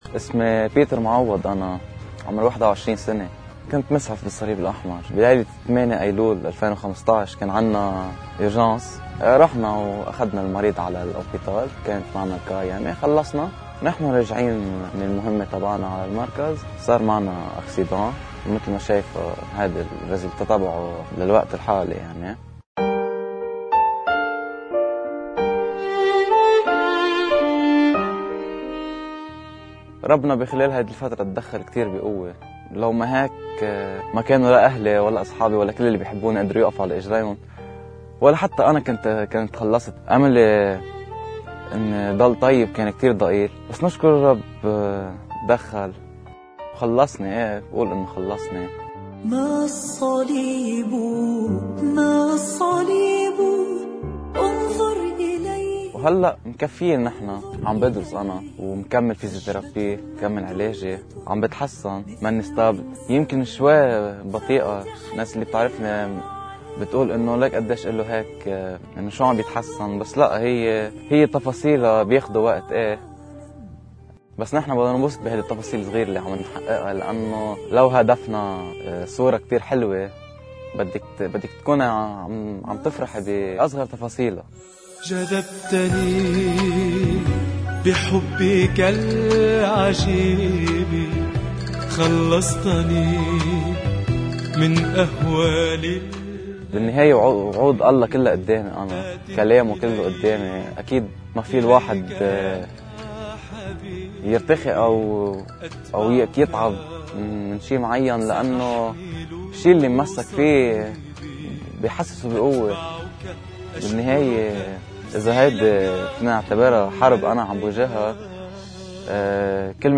حلقة خاصة من دير مار اليشاع وادي  قنوبين مع شهادات حية عاشت معنى القيامة الحقيقية